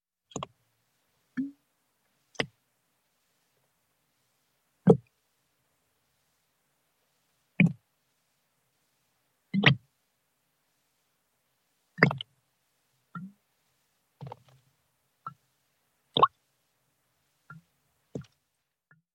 На этой странице собраны звуки кетчупа, майонеза и других соусов — от хлюпающих до булькающих.
Звук вытекающего кетчупа из жирной банки